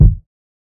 Kick Dilla.wav